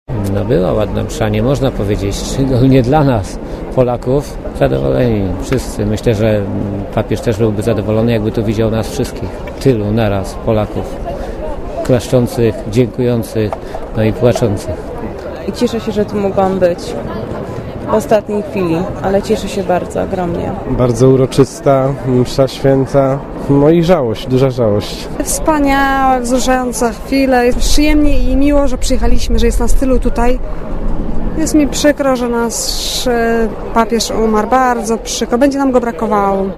Polacy z różnych stron Polski po pogrzebie Ojca Świętego utworzyli na placu św. Piotra w Rzymie spontaniczny krąg, w którym modlą się i śpiewają wraz z kapłanami, towarzyszącymi wielu grupom pielgrzymkowym.